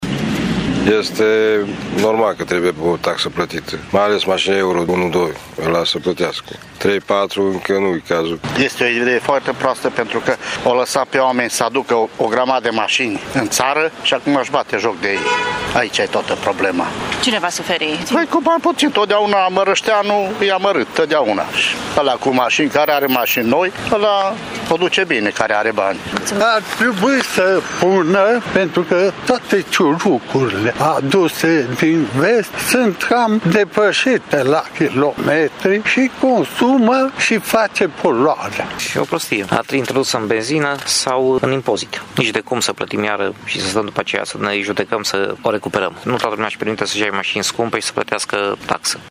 Târgumureșenii au păreri împărțite cu privire la introducerea unei noi taxe de poluare: